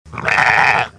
Le mouton | Université populaire de la biosphère
il bêle
mouton.mp3